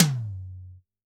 CYCdh_Kurz07-Tom03.wav